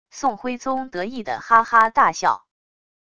宋徽宗得意的哈哈大笑wav音频